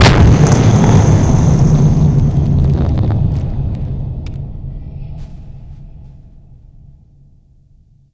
explo20.wav